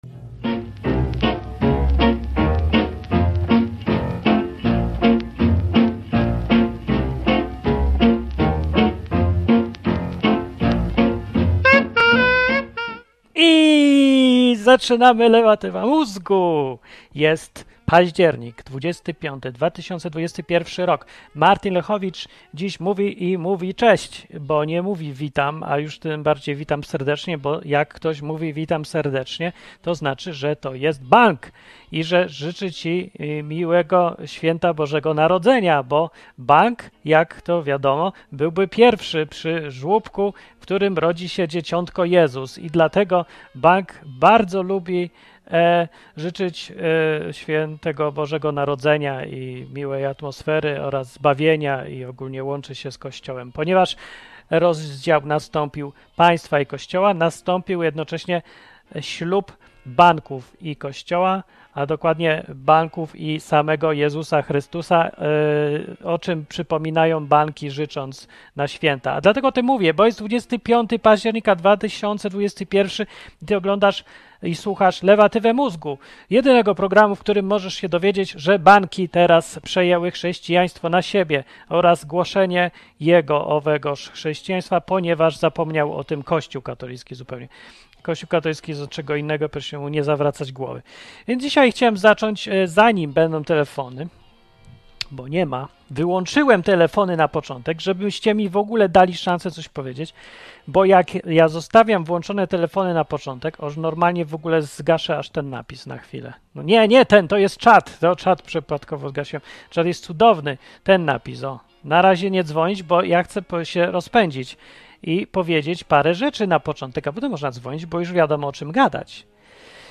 Program satyryczny, rozrywkowy i edukacyjny.